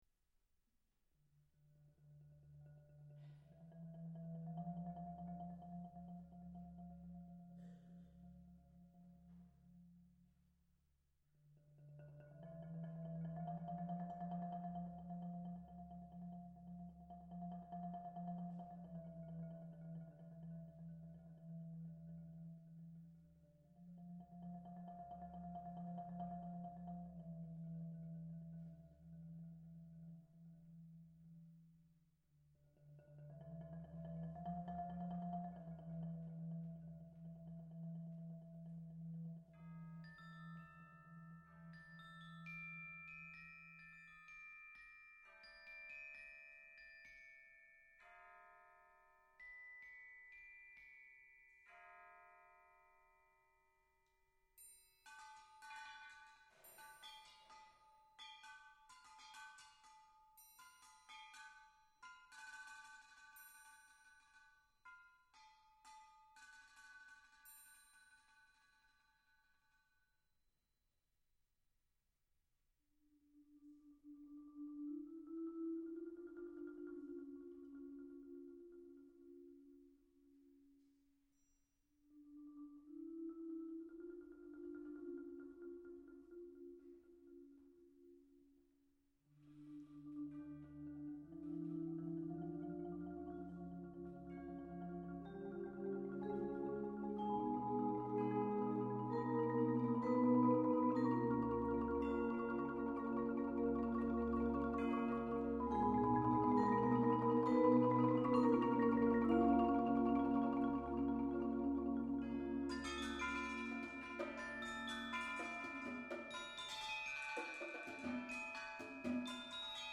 Genre: Percussion Ensemble
Marimba 1 (4.3-octave)
Vibraphone, Crotales
Xylophone, Chimes
Timpani
Percussion 1 (5 brake drums, triangle, bass drum)